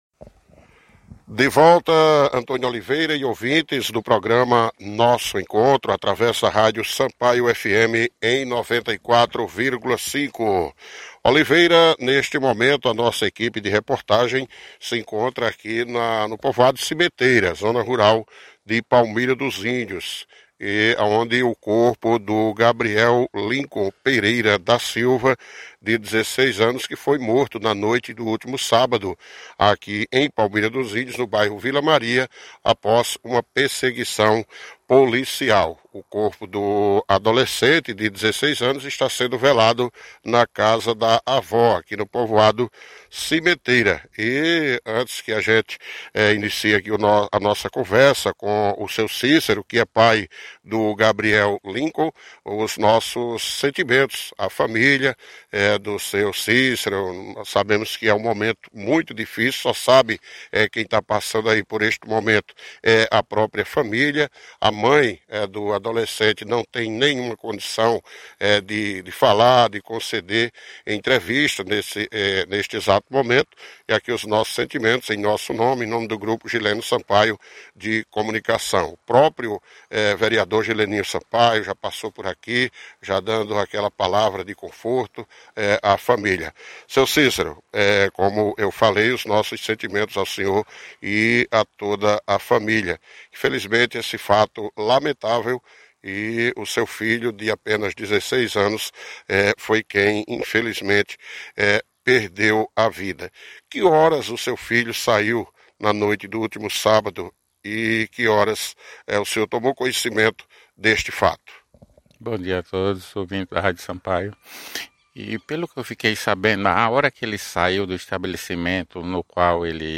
Entrevista-online-audio-converter.com_.mp3